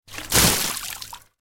دانلود آهنگ آب 42 از افکت صوتی طبیعت و محیط
دانلود صدای آب 42 از ساعد نیوز با لینک مستقیم و کیفیت بالا
جلوه های صوتی